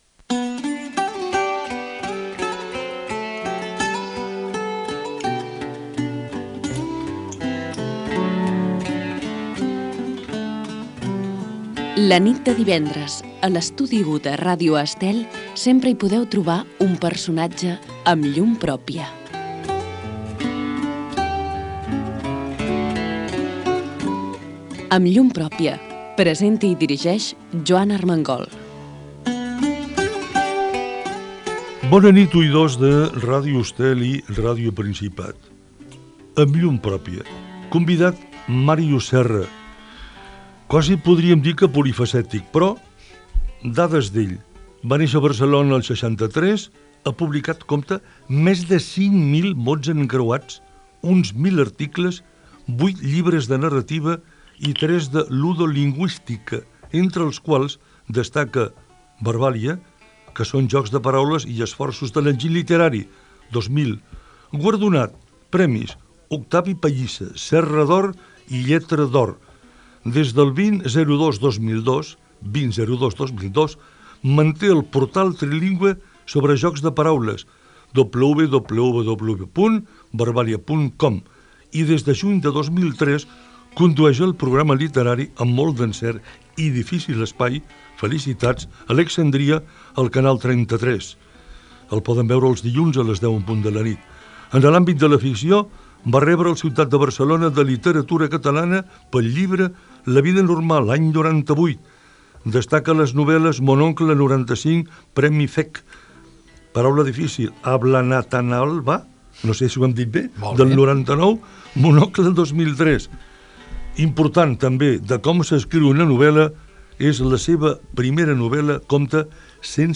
Careta del programa, biografia professional de Màrius Serra i entrevista a l'escriptor sobre la seva família i el programa "Alexandria" del Canal 33
Entreteniment